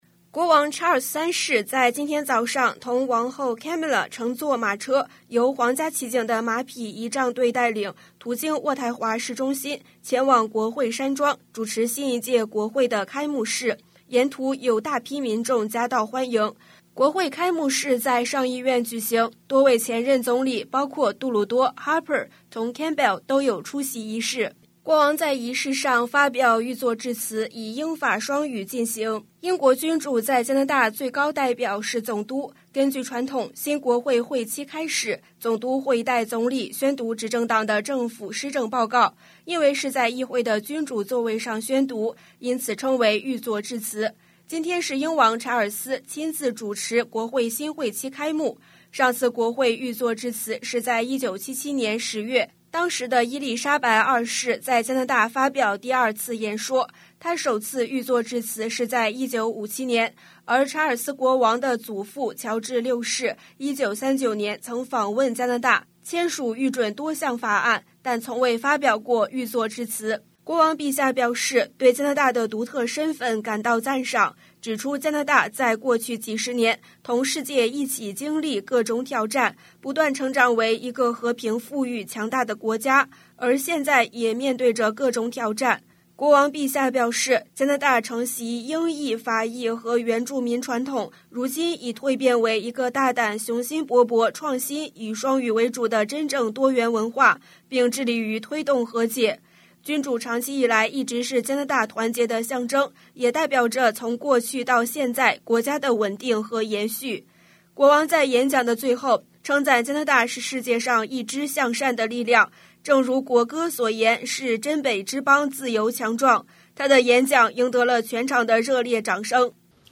news_clip_23570_mand.mp3